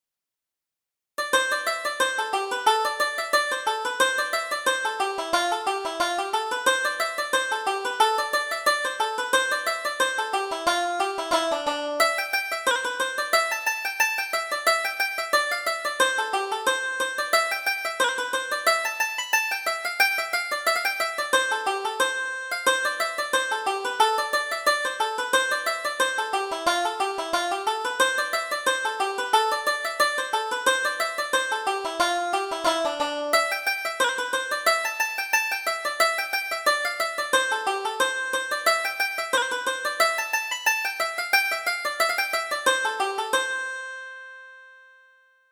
Reel: My Maryanne